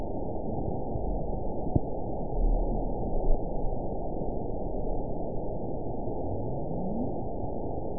event 921620 date 12/11/24 time 04:46:18 GMT (5 months ago) score 8.64 location TSS-AB04 detected by nrw target species NRW annotations +NRW Spectrogram: Frequency (kHz) vs. Time (s) audio not available .wav